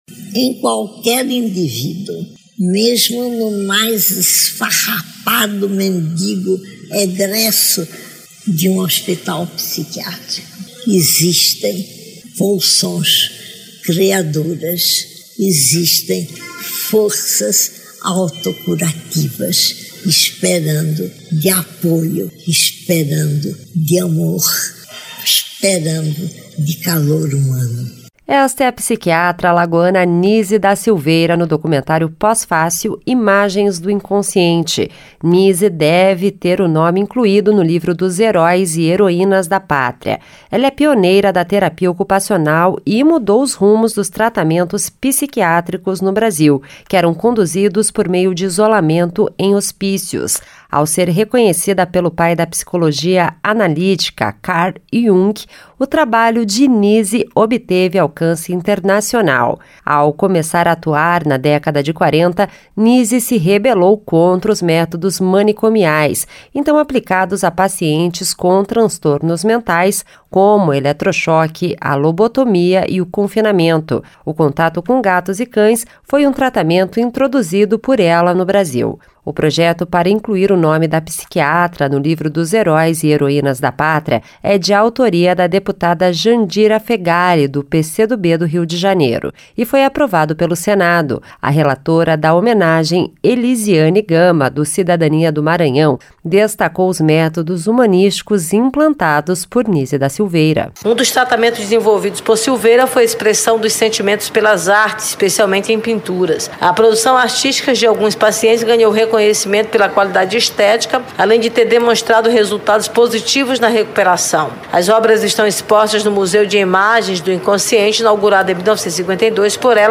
Esta é a um trecho da fala da psiquiatra alagoana Nise da Silveira no documentário Posfácil – Imagens do Inconsciente, de Leon Hirszman.